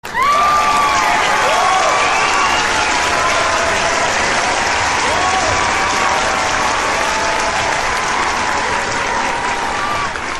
kalabalk_alks_sesi_uzun_versiyon_ozel_kayit_hd_-online-audio-converter.mp3